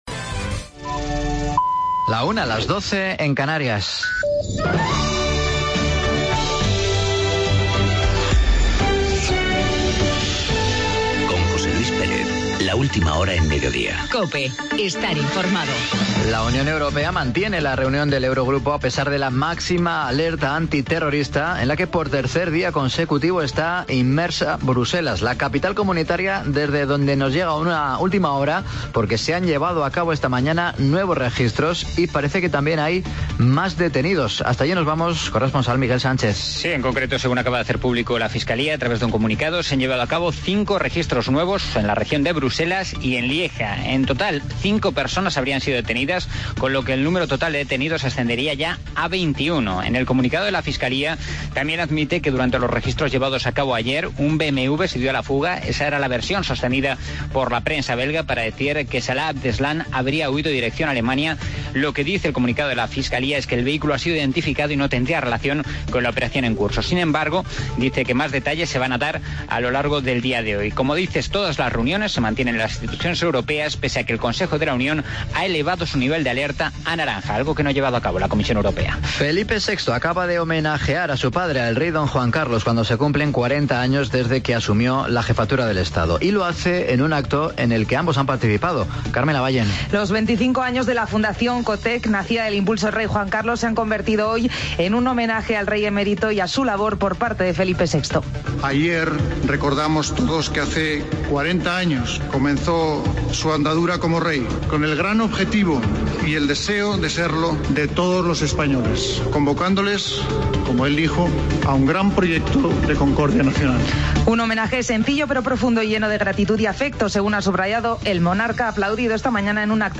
Boletín informativo